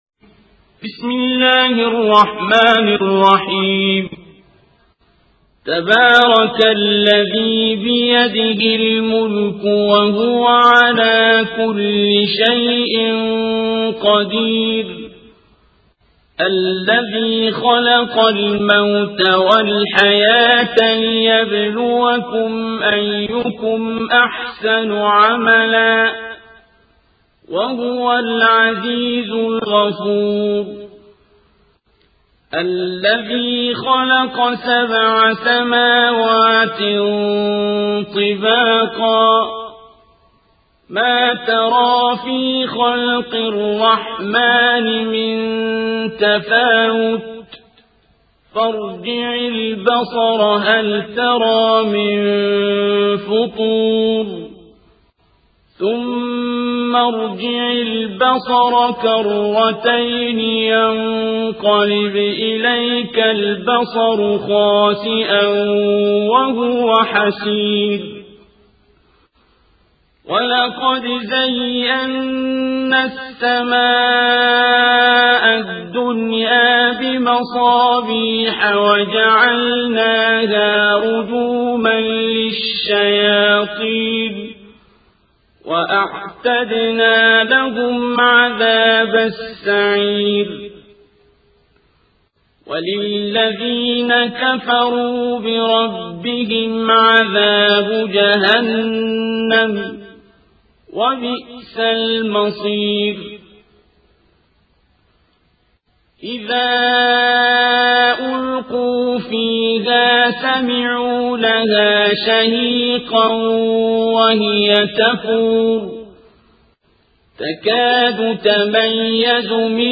القارئ: الشيخ عبدالباسط عبدالصمد